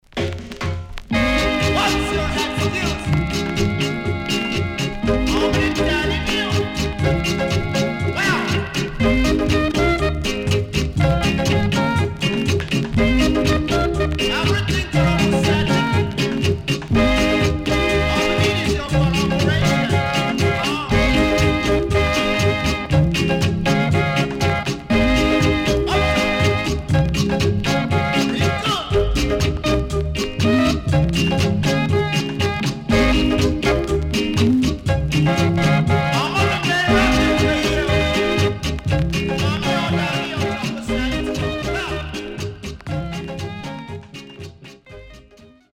CONDITION SIDE A:VG(OK)〜VG+
Great Early Reggae Vocal & Inst.Skinheads
SIDE A:うすいこまかい傷ありますがノイズあまり目立ちません。